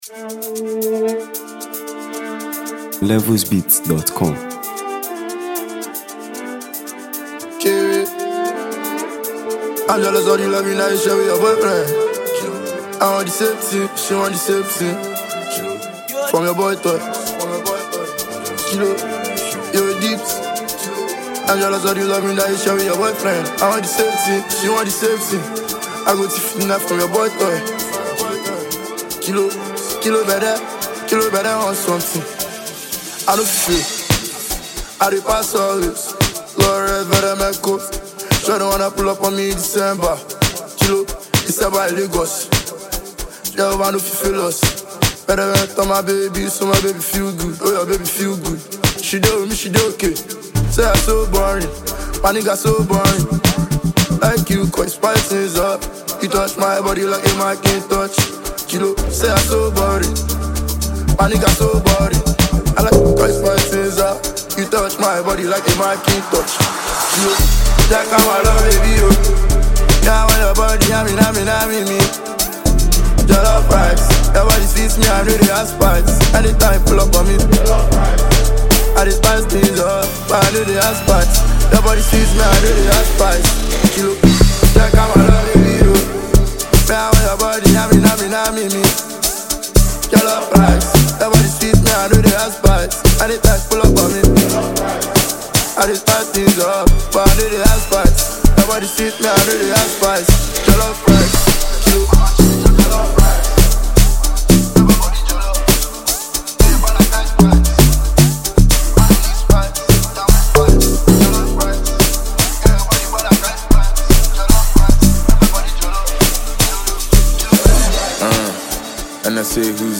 Nigeria Music 2025 3:07
Afrobeat-infused rap